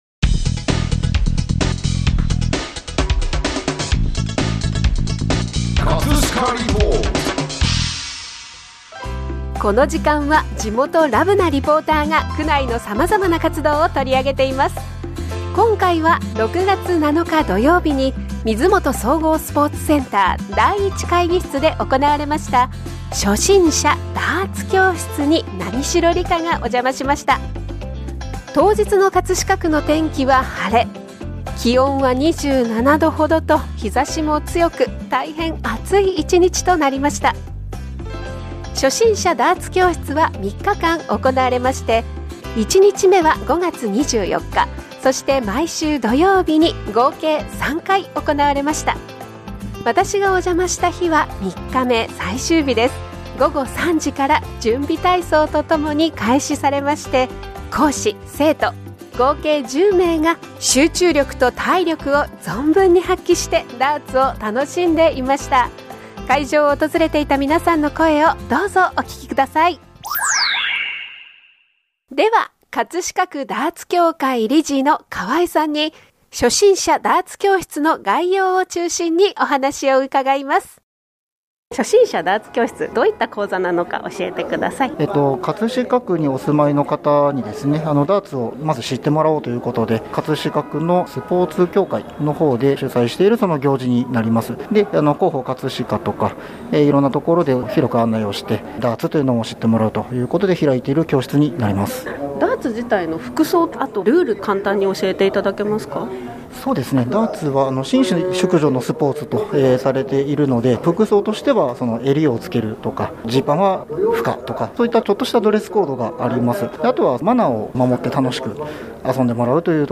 【葛飾リポート】 葛飾リポートでは、区内の様々な活動を取り上げています。
会場の皆さんの声をどうぞお聴きください。